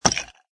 woodice2.mp3